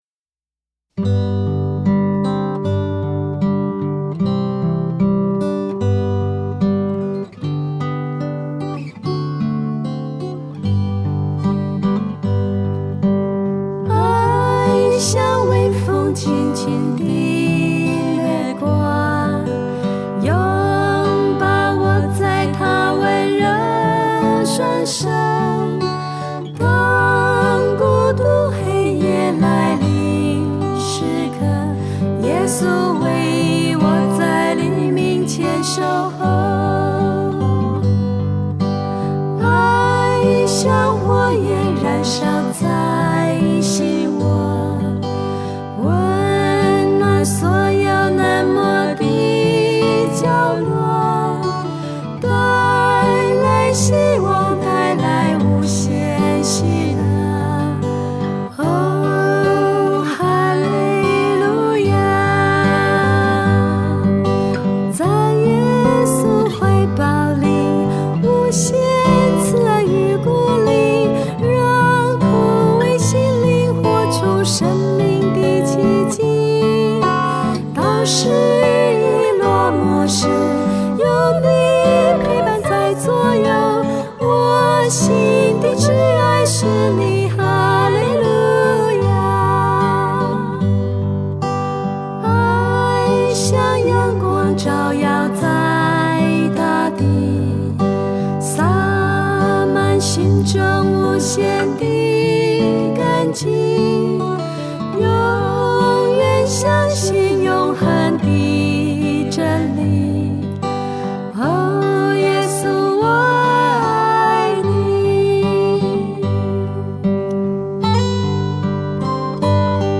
鋼琴、Midi演奏
錄音室：動物園錄音室